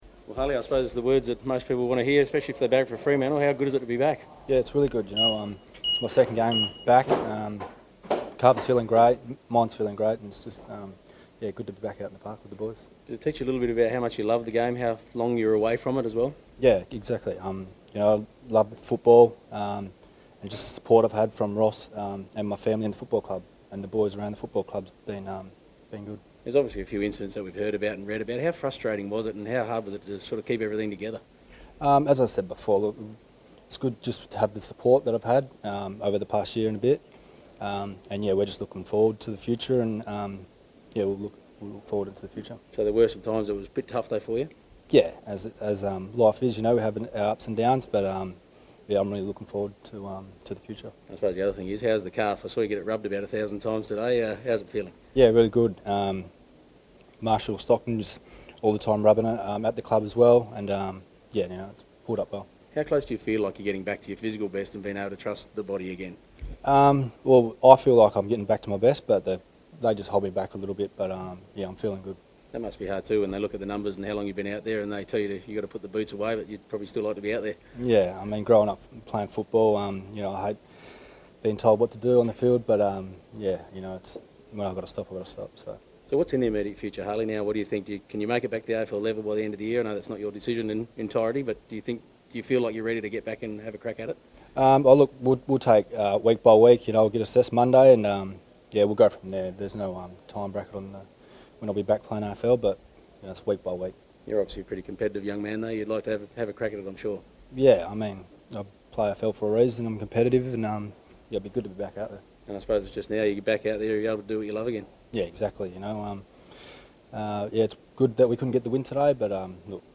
Harley Bennell spoke to the media after playing for Peel on Saturday